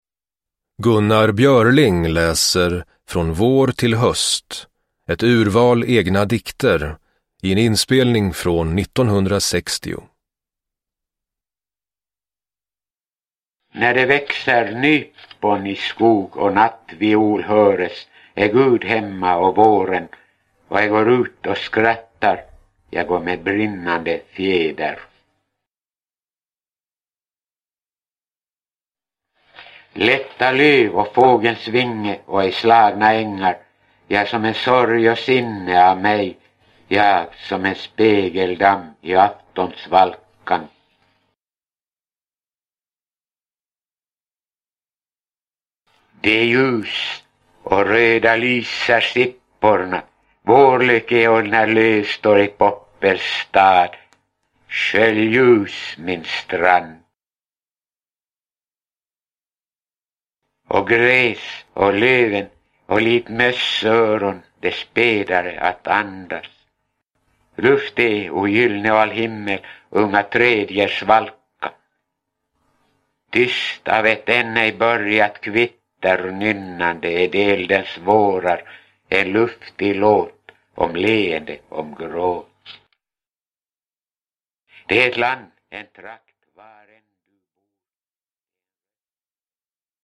Produkttyp: Digitala böcker
Björlings lyrik är vacker att lyssna till när han själv läser, men när den först läses inte så lätt att tillägna sig.
Uppläsare: Gunnar Björling